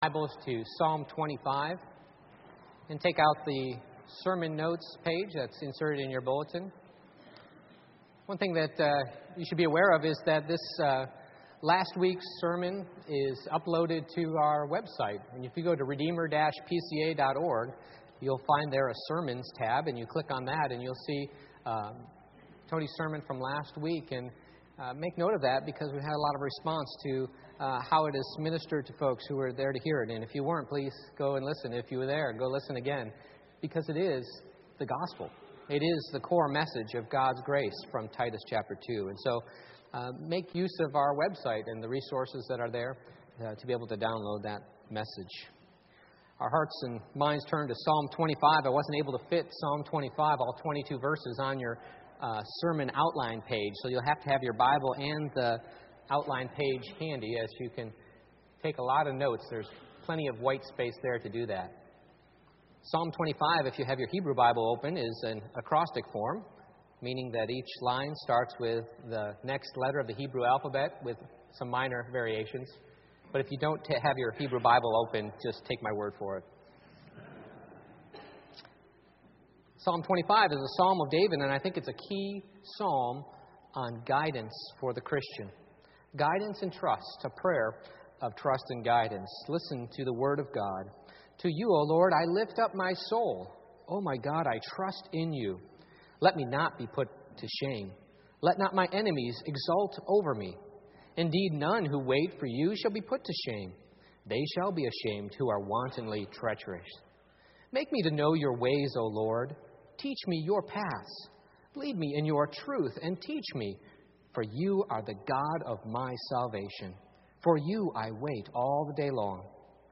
Psalm 25:1-22 Service Type: Morning Worship I. Trusting God with Your Enemies